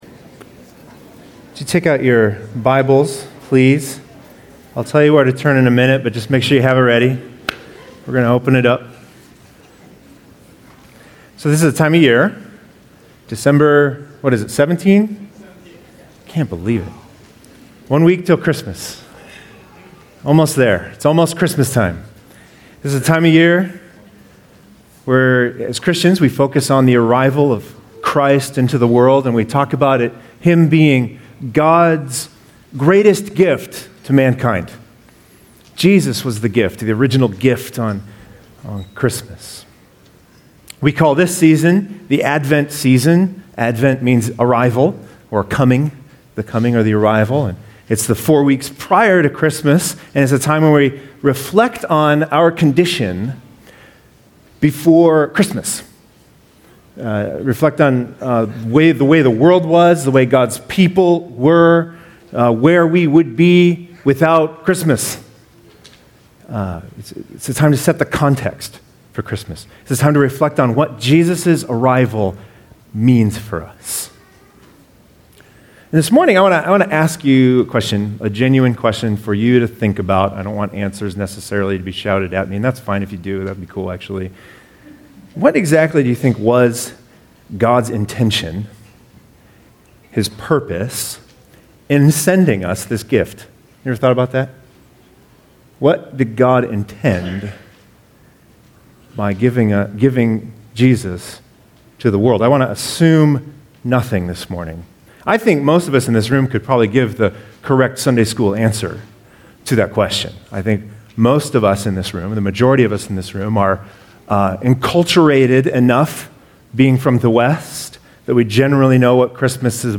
Priest and King Download sermon notes and discussion questions.